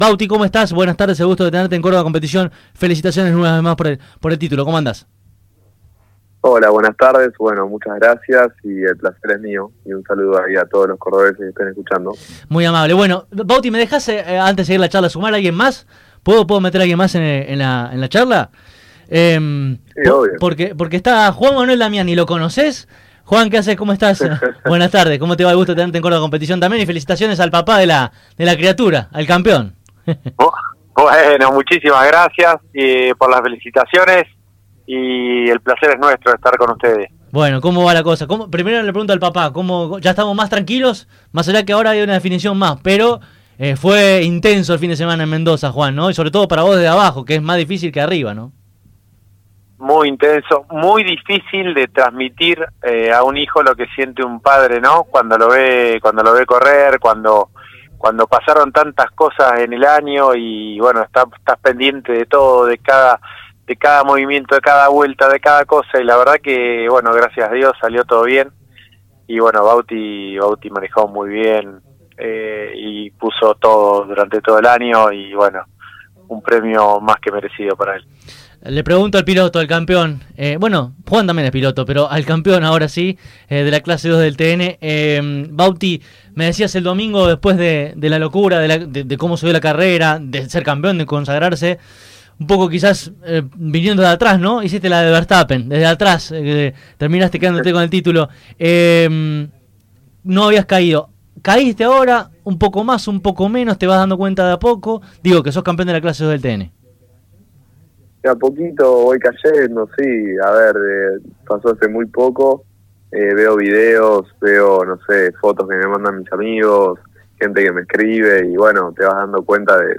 Escuchá aquí debajo esta emotiva charla de manera completa: